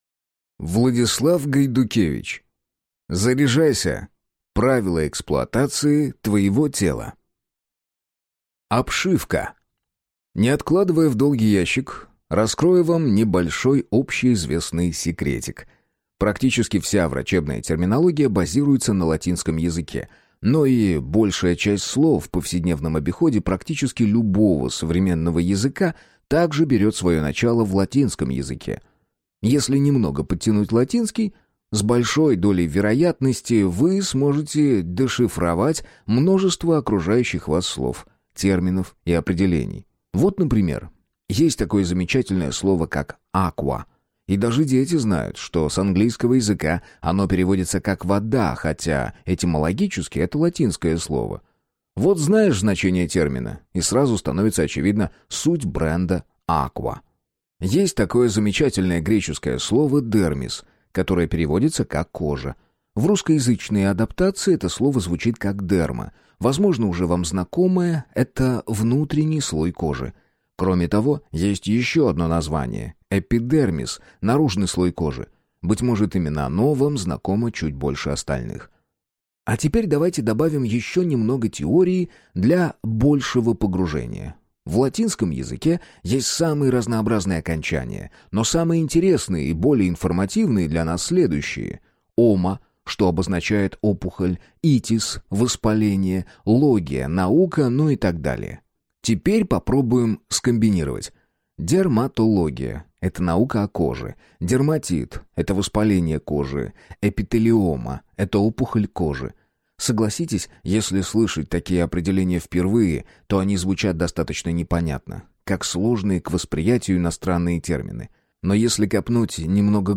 Аудиокнига Заряжайся! Правила эксплуатации твоего тела | Библиотека аудиокниг